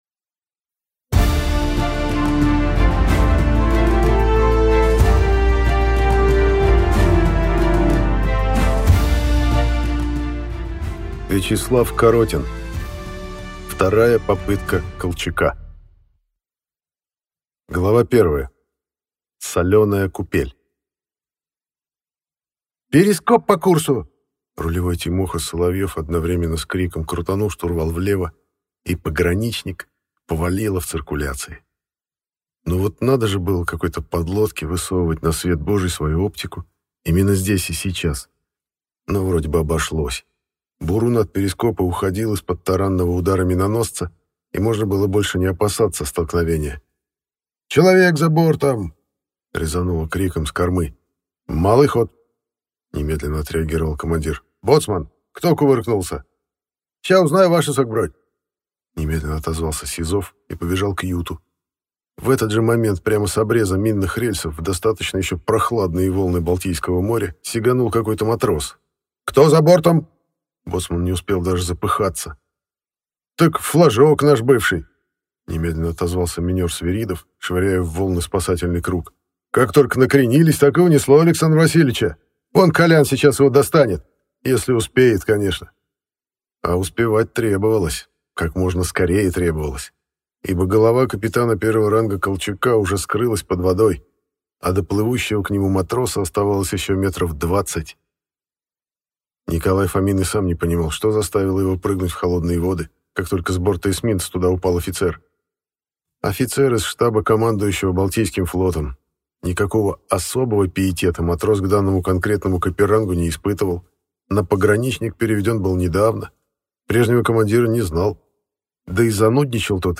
Аудиокнига Вторая попытка Колчака | Библиотека аудиокниг